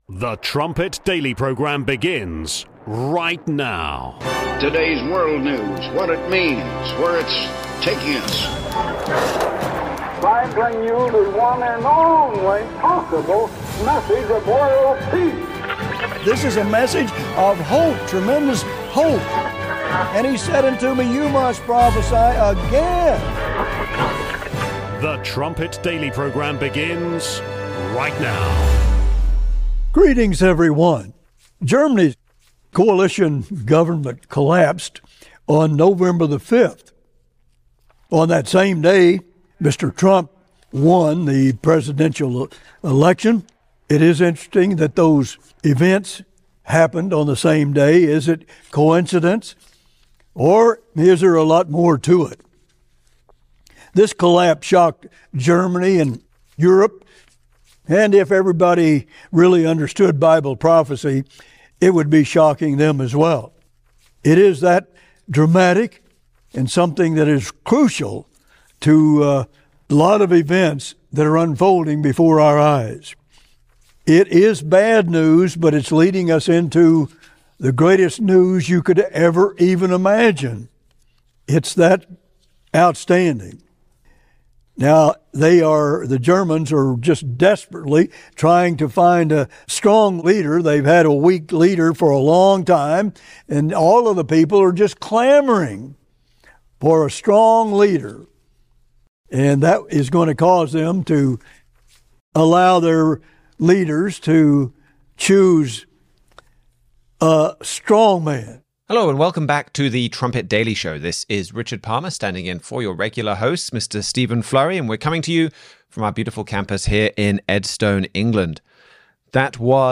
Germany’s coalition government is struggling again, leading Germans to seek a strong leader. Key of David clips from over the years provide important context for Germany’s present and future.